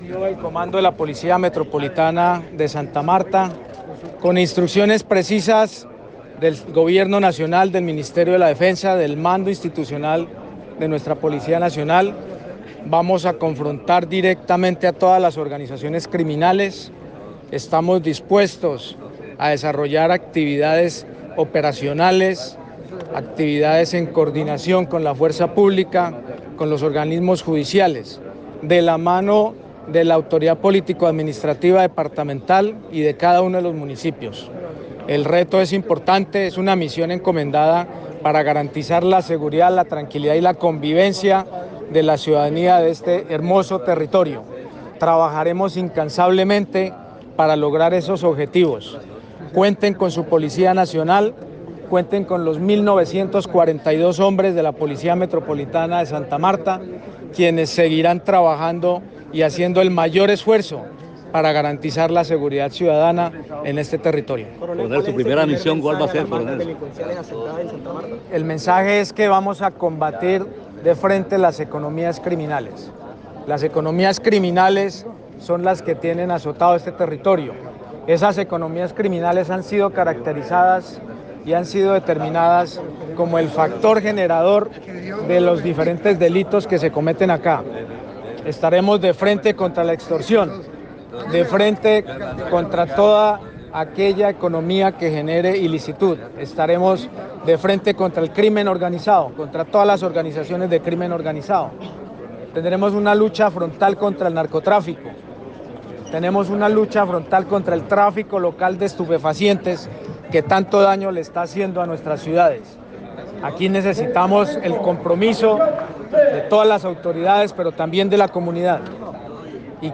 Estas son las declaraciones al finalizar la transmisión de mando en la Quinta De San Pedro Alejandrino.